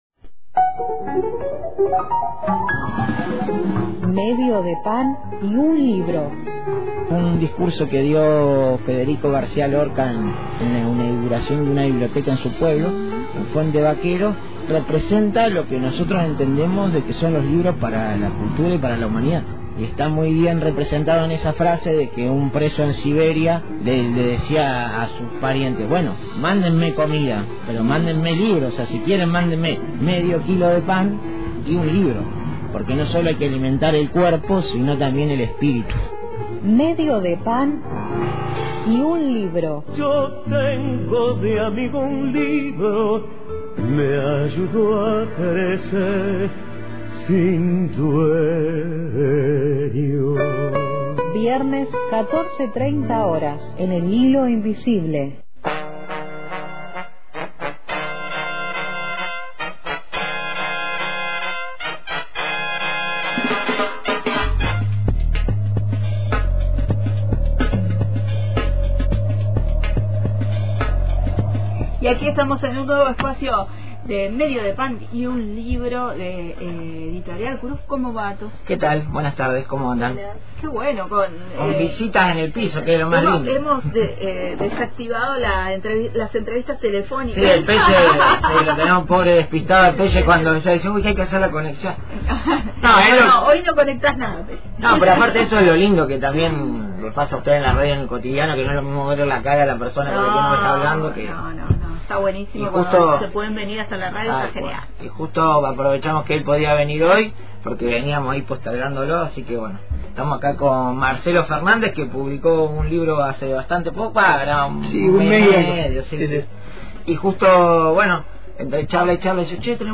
estuvimos en el estudio